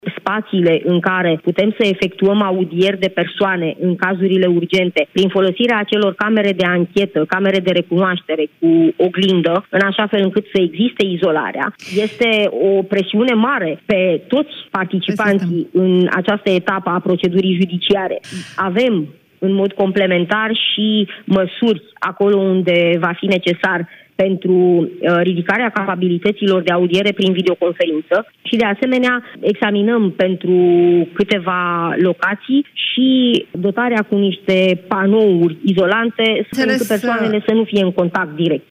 Procurorul general al României, Gabriela Scutea, în emisiunea Piața Victoriei: